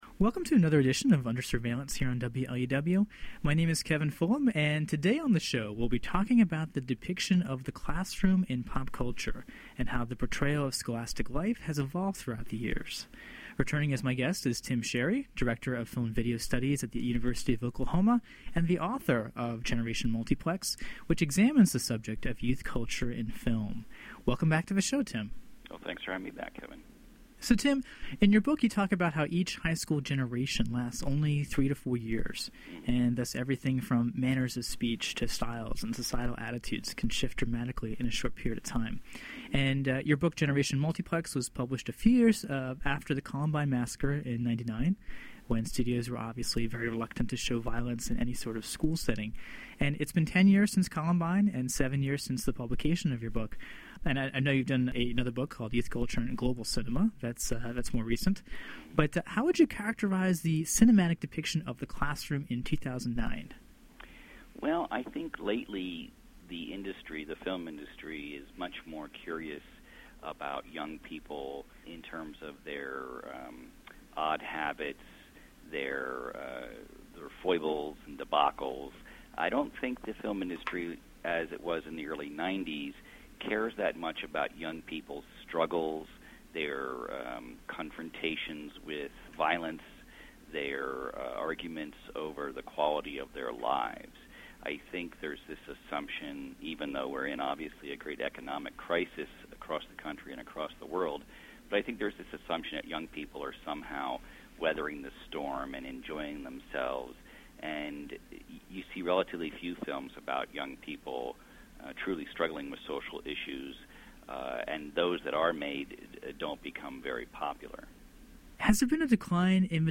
[Originally broadcast on WLUW’s Under Surveillance in April 2009.] https